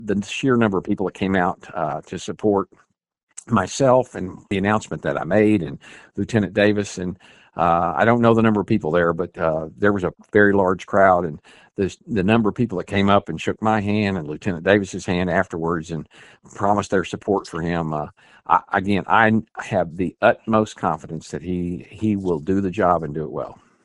Sheriff Montgomery tells KTLO, Classic Hits and The Boot News he was taken aback by the turnout at his announcement.